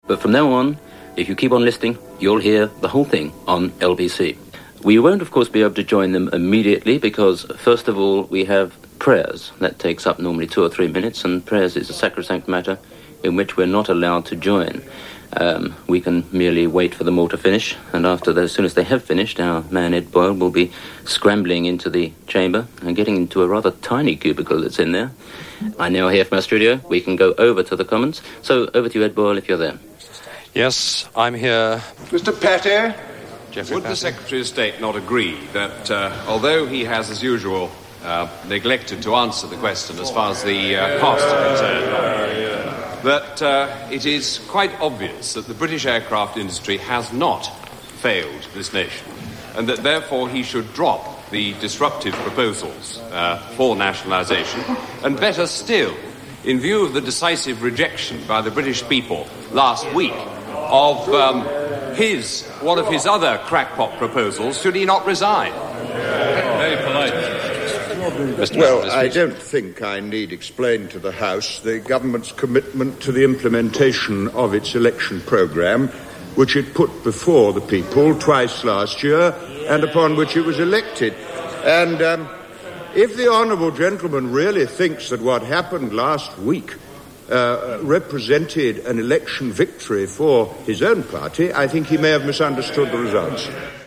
This broadcast experiment was deemed a success, despite normal folk being variously surprised or annoyed at the din heard.
On 3rd April 1978, the permanent broadcasting of the Commons began (heard here) with the Secretary of State for Wales answering questions about the Welsh language.